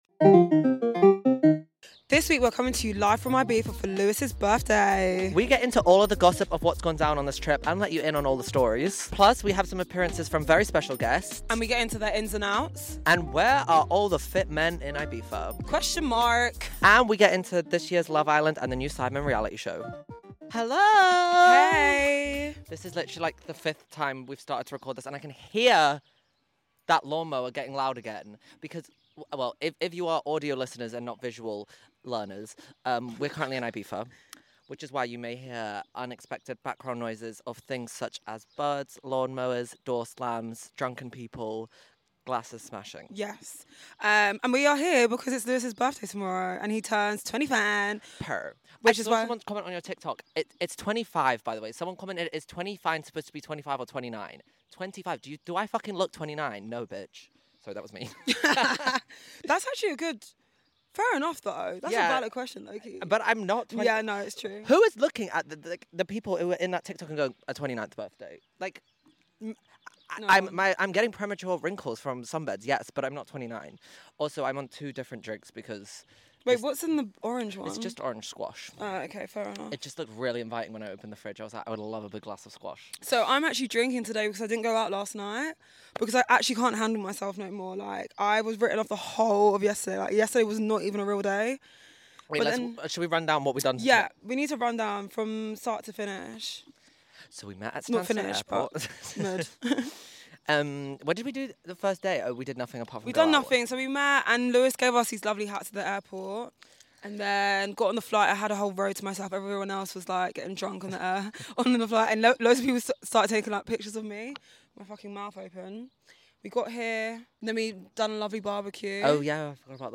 This week we are coming to you live (& tipsy) from Ibiza!